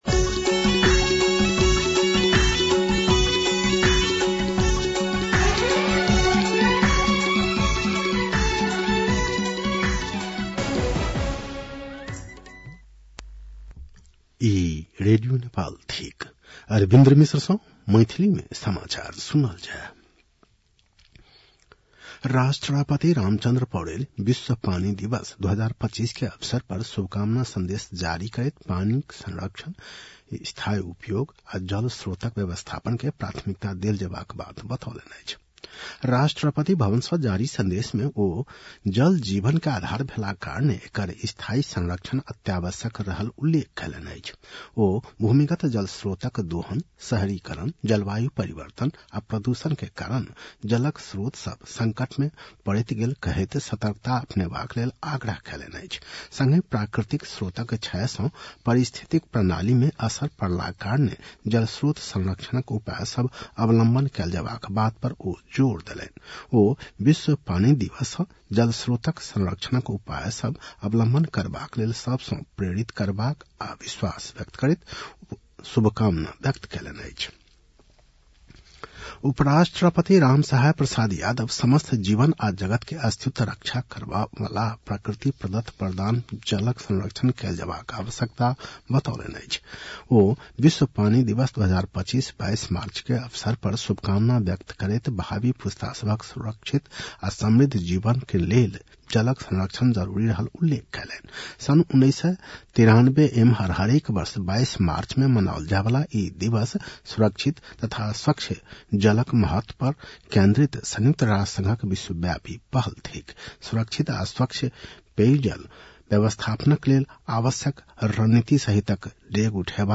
मैथिली भाषामा समाचार : ९ चैत , २०८१